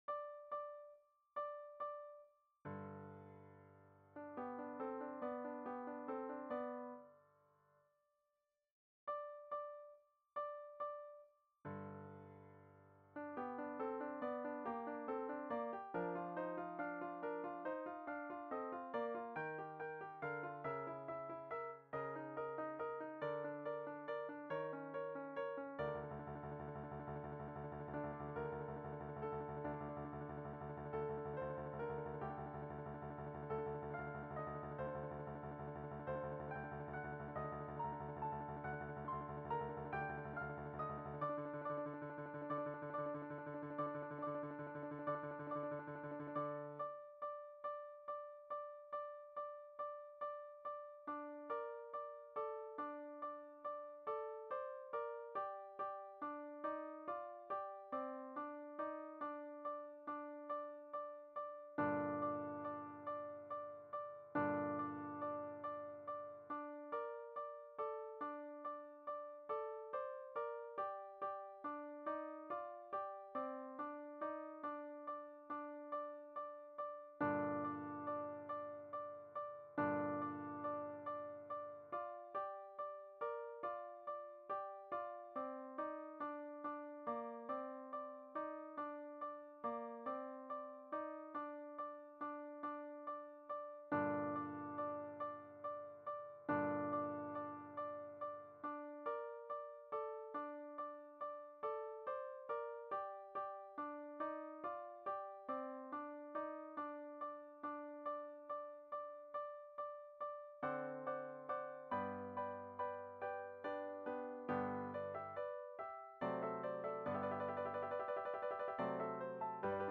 Tenor1 Tenor2 Bass